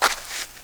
SAND 1.WAV